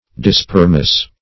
Search Result for " dispermous" : The Collaborative International Dictionary of English v.0.48: Dispermous \Di*sper"mous\ (d[-i]*sp[~e]r"m[u^]s), a. [Gr. di- = dis + spe`rma seed, fr. spei`rein to sow: cf. F. disperme.]
dispermous.mp3